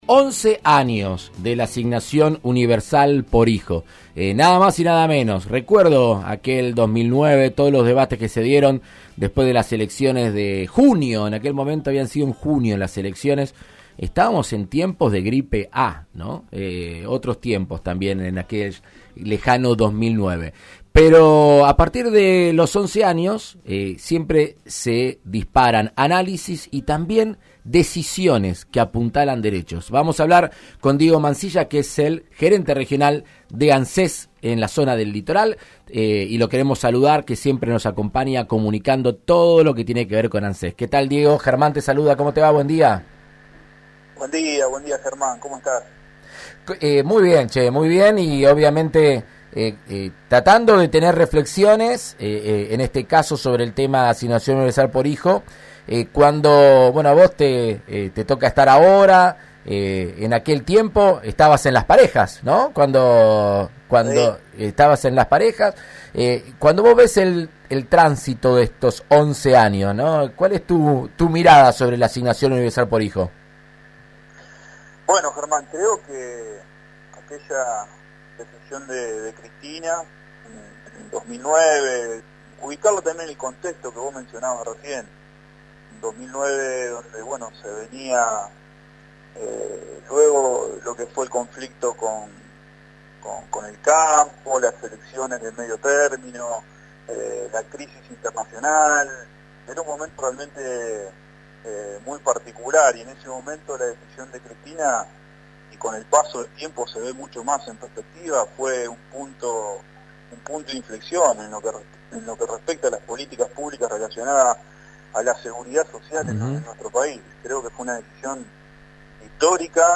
El jefe de Anses a cargo de la Región Litoral, Diego Mansilla, habló con el diputado nacional Germán Martínez en Argentina Unida contra el Coronavirus sobre la ampliación de la Asignación Universal por Hijo a una gran cantidad de beneficiarios que habían quedado afuera en los últimos años.